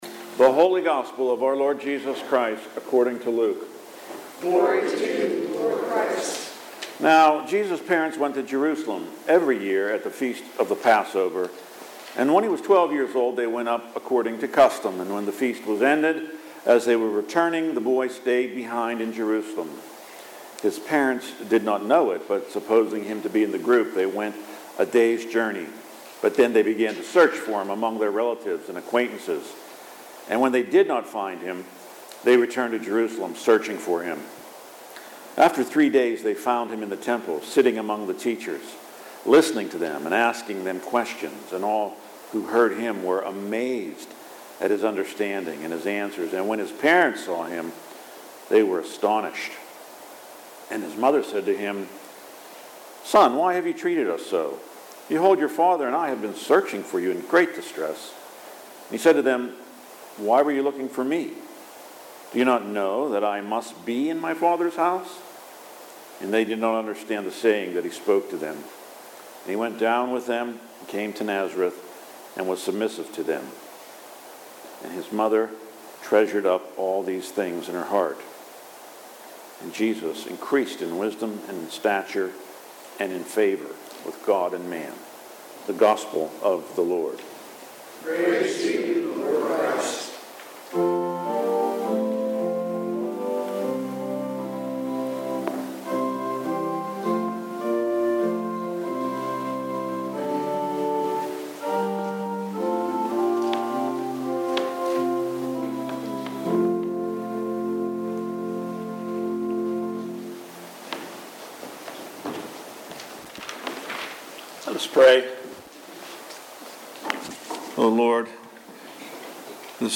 Readings and Sermon January 4, 2026 – Saint Alban's Anglican Church